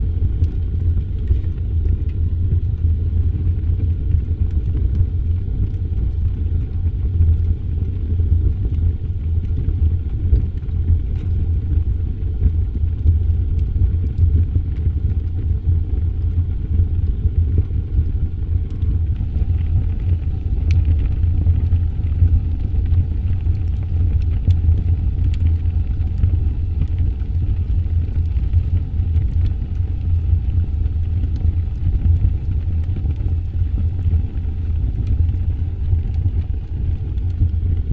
ambientFire1.ogg